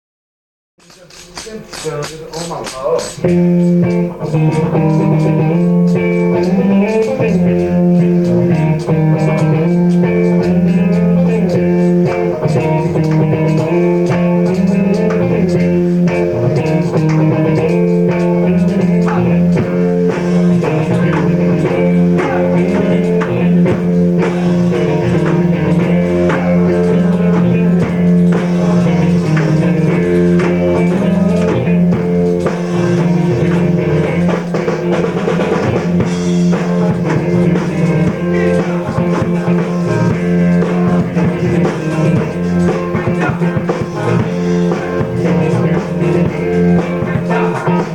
The group of cartoonists gathered in this D.I.Y. workshop was involved in various experiments, including playing music together.
This recording was taken from a video tape (we simply switched on the video camera while we played), and the track that you can hear is the result of sheer improvisation At one point we just started to play a little tune that seemingly came out of nowhere, and we liked it even more when we later listened to the tape.